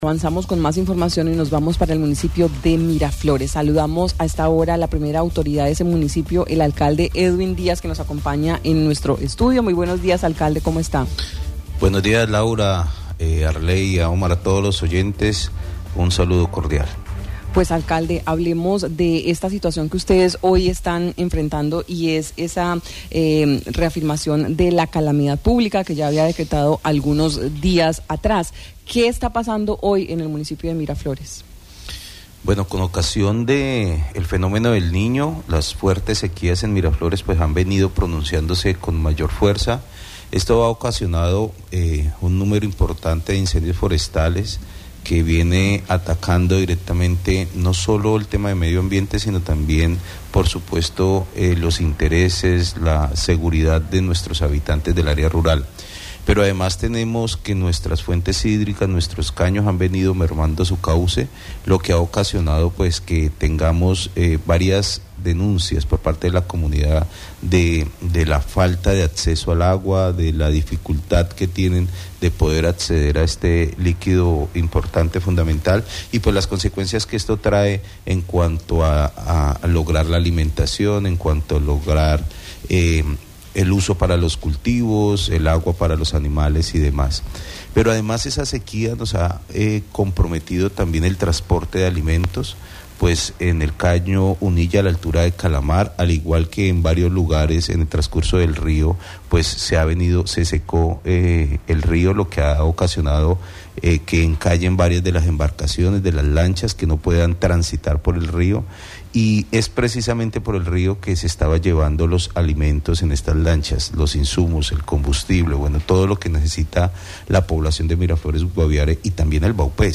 Edwin Ioanny Díaz, alcalde del municipio de Miraflores, habló sobre la situación que se presenta en ese municipio a causa del fuerte verano, donde ya el rio no es navegable a causa de la sequía, esto ha interrumpido llevar hasta allí los artículos de consumo diario y otros elementos.